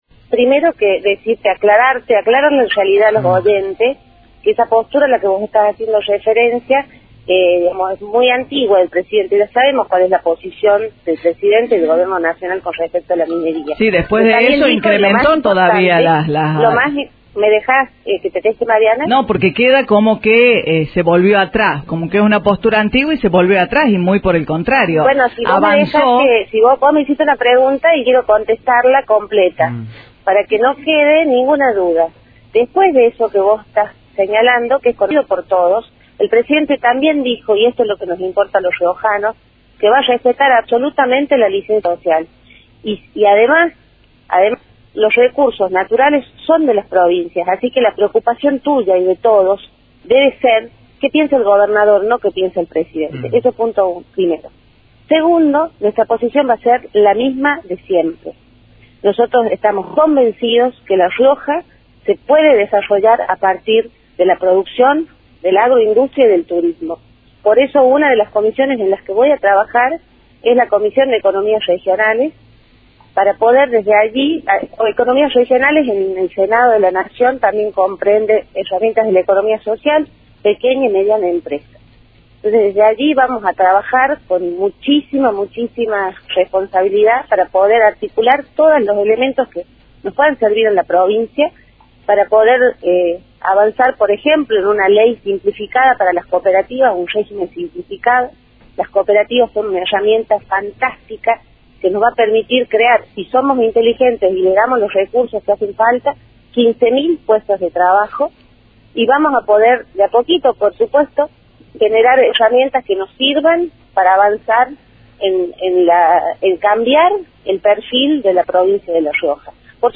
Brizuela y Doria, que ganó las últimas elecciones legislativas junto al ex ministro de Defensa Julio Martínez, se mostró molesta ante la pregunta de Radio 24 de La Rioja.
«No. Nosotros vamos a defender siempre lo que hemos defendido», afirmó en forma tajante la legisladora electa.
Las declaraciones de Brizuela y Doria